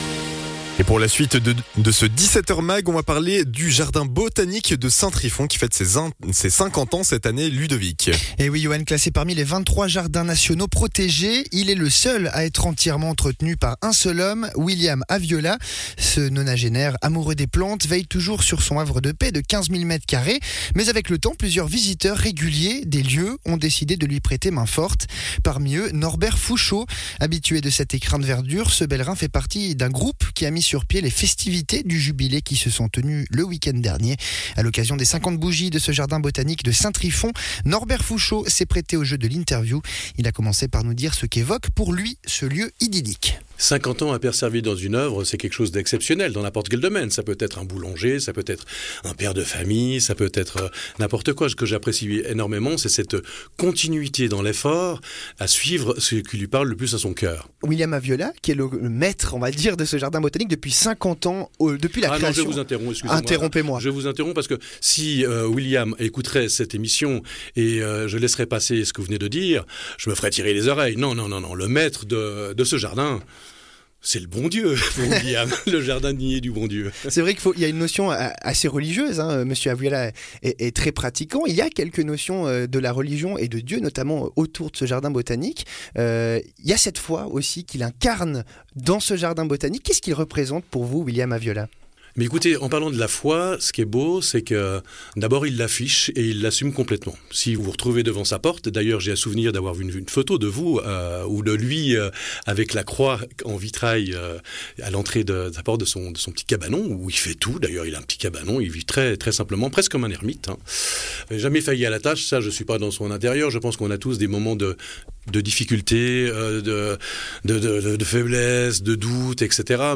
membre bénévole